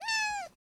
CatMeow.wav